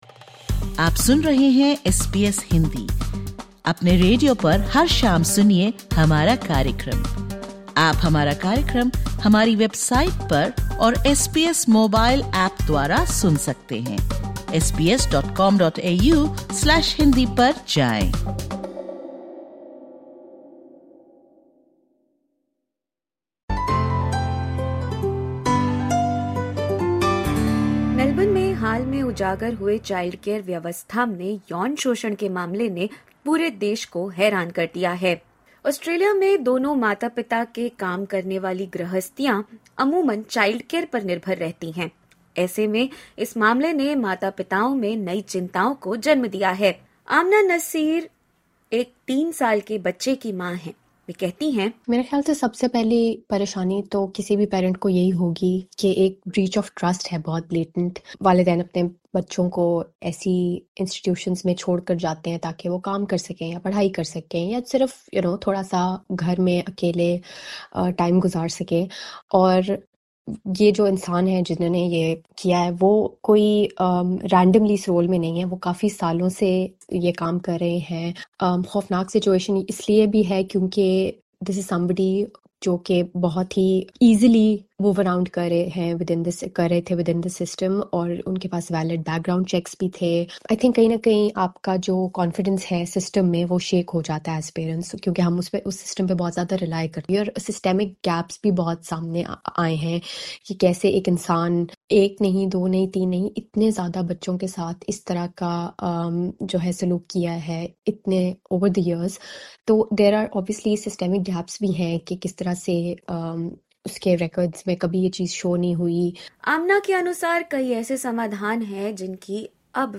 DISCLAIMER: The information expressed in this interview is of general nature.